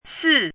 chinese-voice - 汉字语音库
si4.mp3